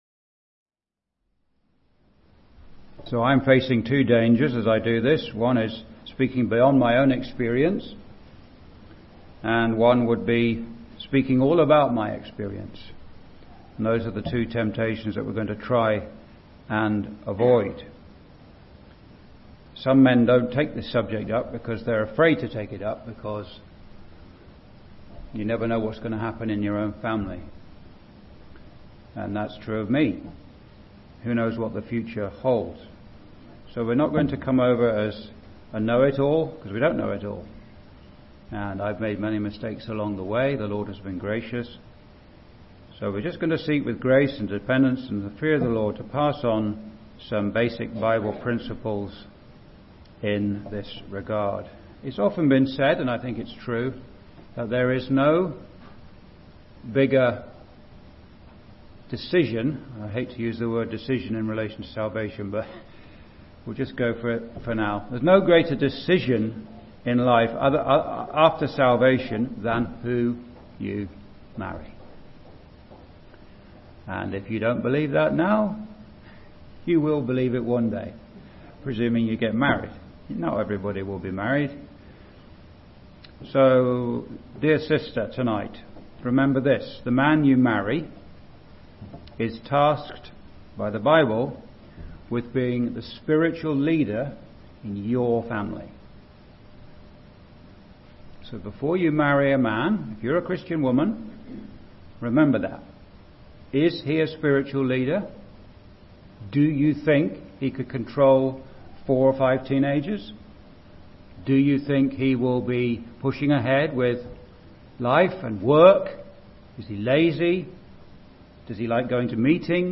(Recorded in Straffordville Gospel Hall, ON, Canada, on 5th Jan 2026)